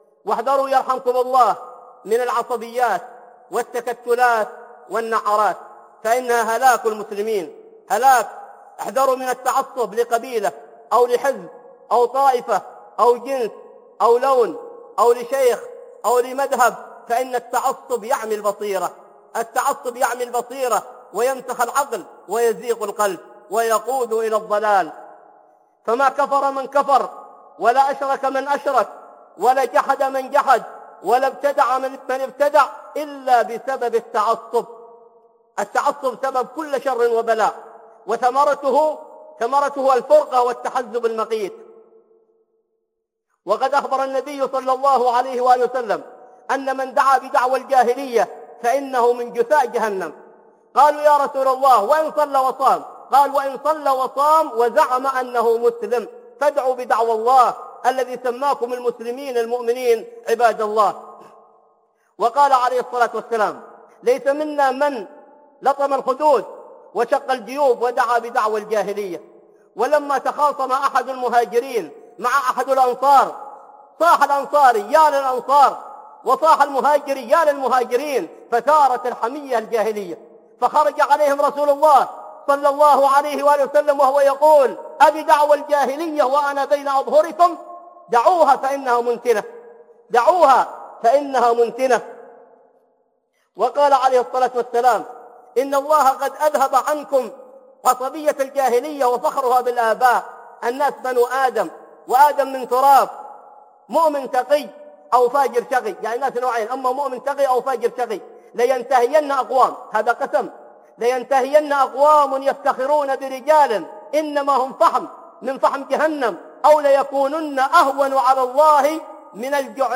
التعصب من صفات الجاهلية - خطب